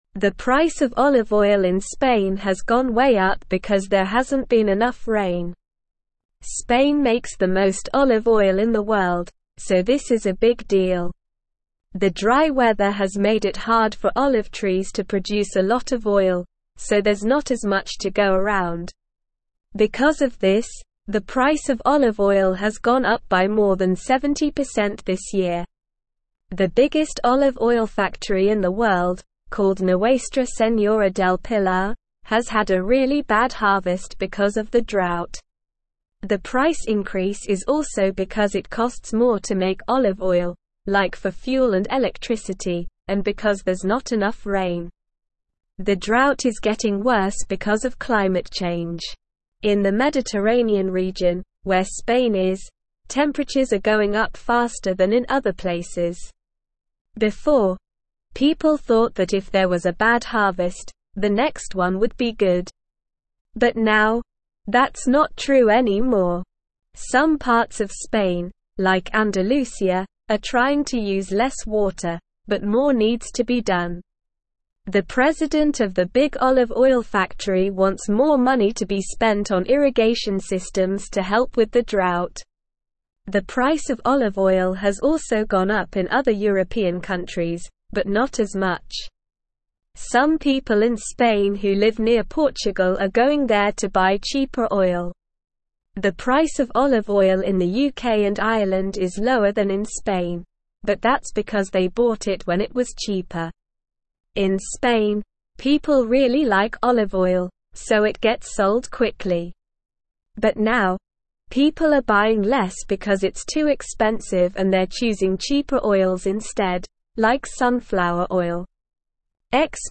Slow
English-Newsroom-Upper-Intermediate-SLOW-Reading-Skyrocketing-Olive-Oil-Prices-in-Spain-Due-to-Drought.mp3